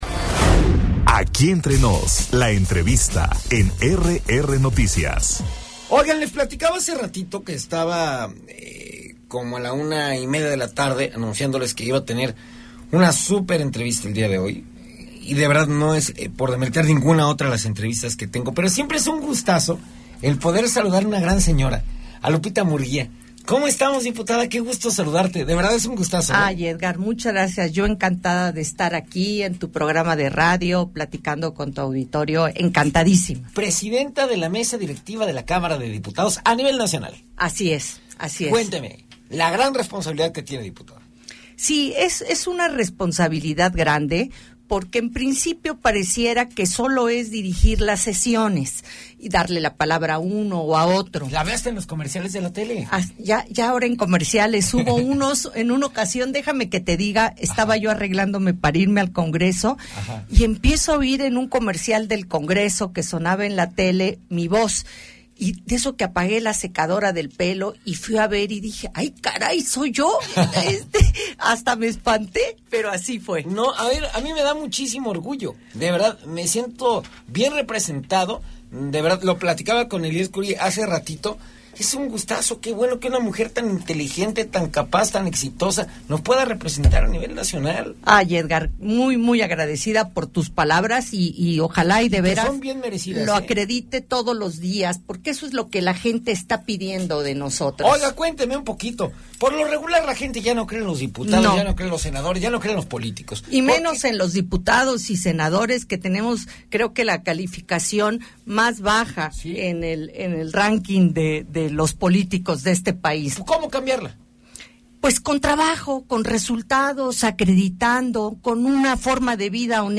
En entrevista Guadalupe Murguía, presidenta de la Cámara de Diputados Federal - RR Noticias